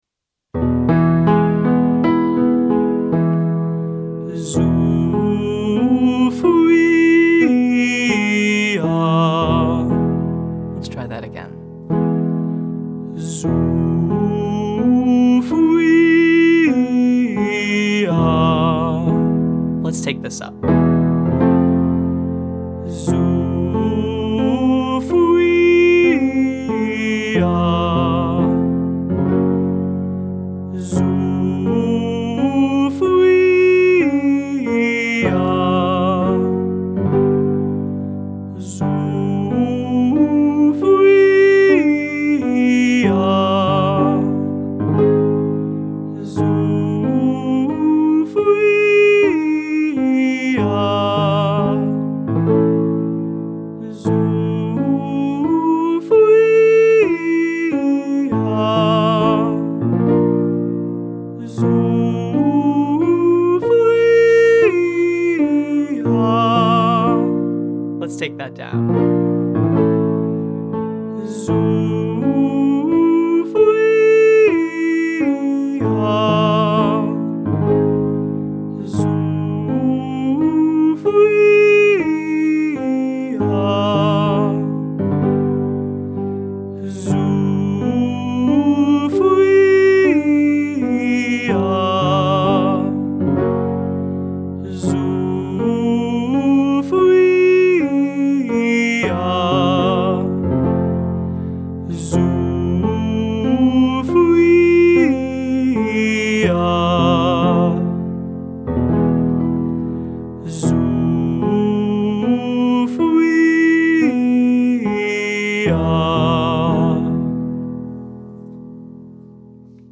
• Zoooffweeeahh (1, 3, 5, 8, 5, 3, 1)